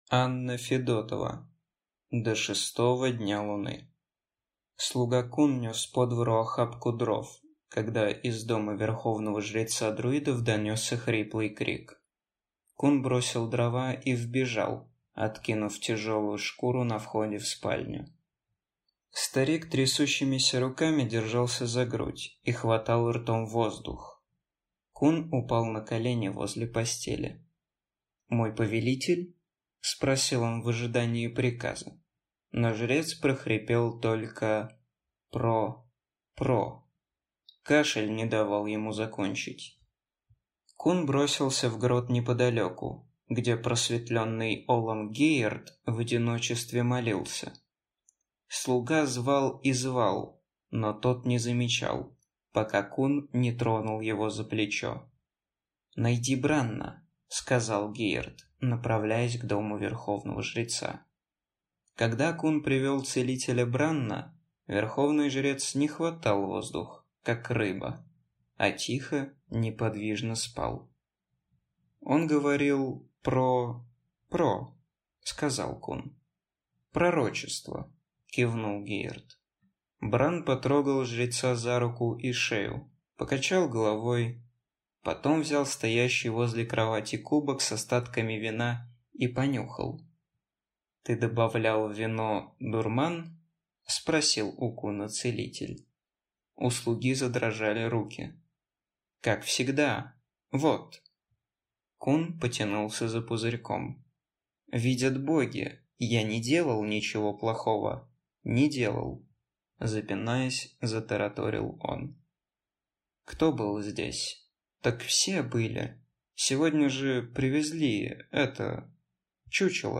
Аудиокнига До шестого дня луны | Библиотека аудиокниг